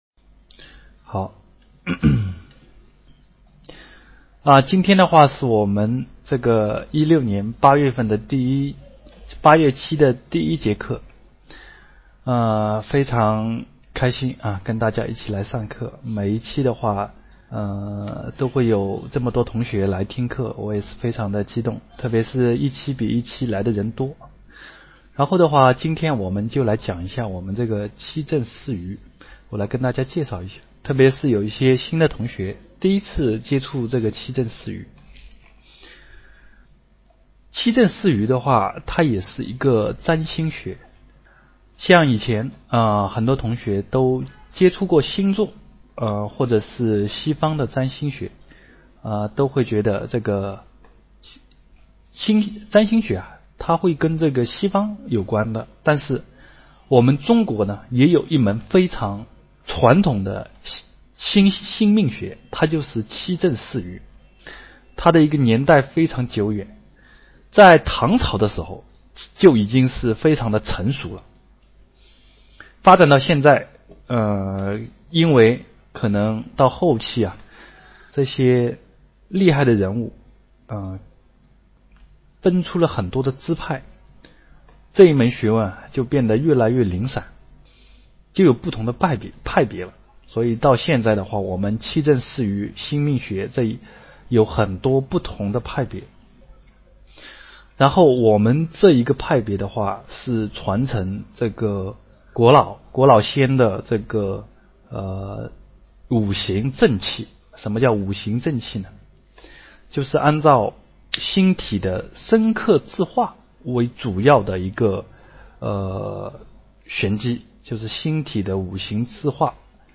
佚名七政四余2016年8月课程录音及资料清晰
佚名七政四余2016年8月课程录音及资料清晰百度网盘分享下载（未知作者，录音清晰）